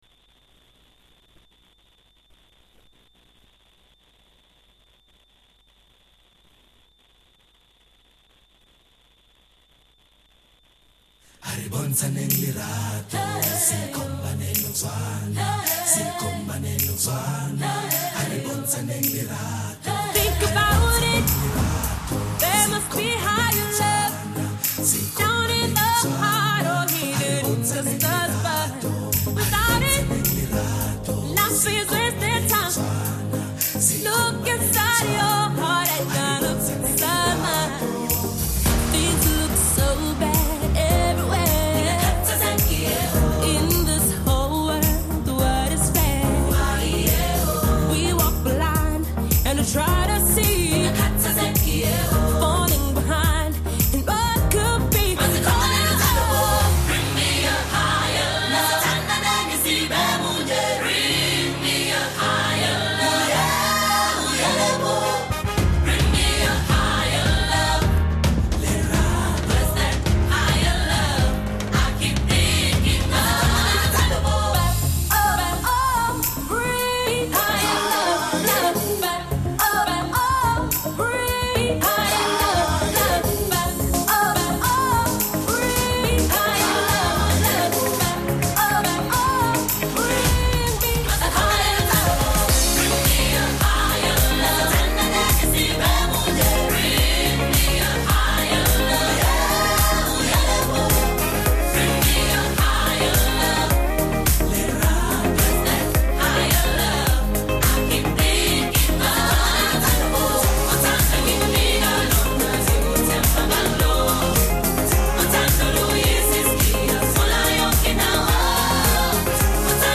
Matters of Faith,songs to uplift, inspire and bring a point of difference